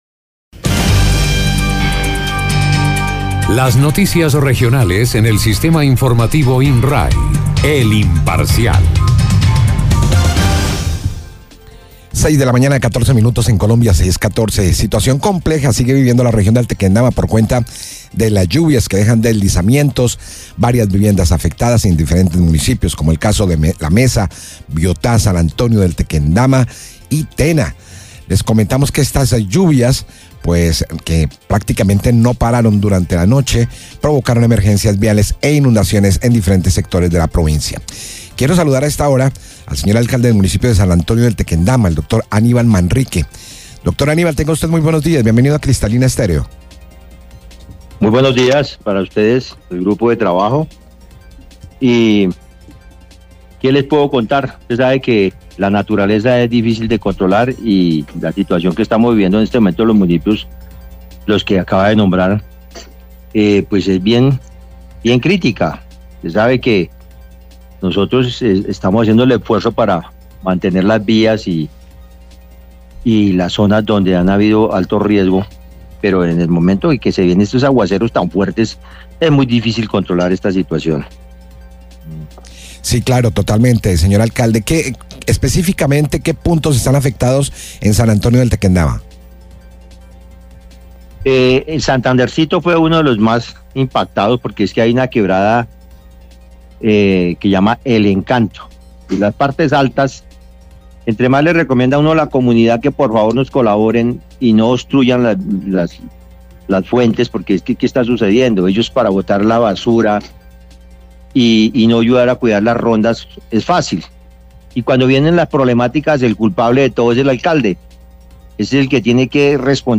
AUDIO_NOTICIA_LLUVIAS_nuevo.mp3